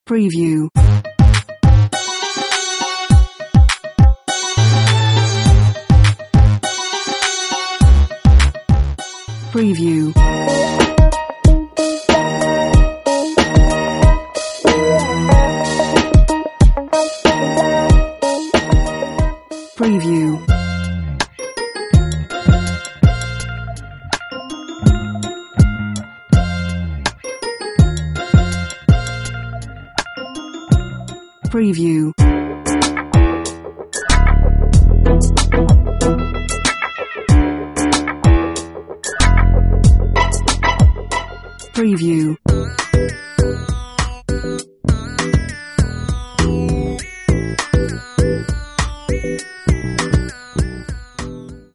East Coast Hip Hop Apple Loops